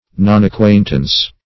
Meaning of nonacquaintance. nonacquaintance synonyms, pronunciation, spelling and more from Free Dictionary.
nonacquaintance.mp3